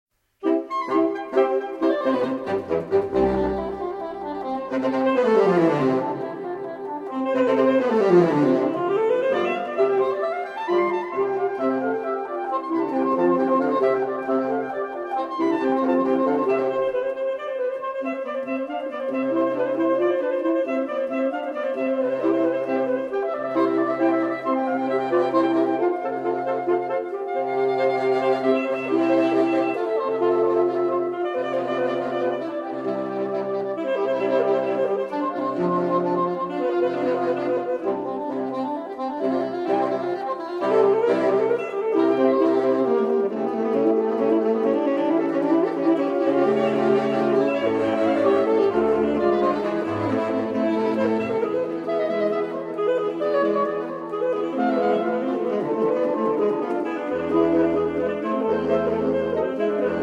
live recordings
baritone saxophone
tenor saxophone
alto saxophone
soprano and alto saxophone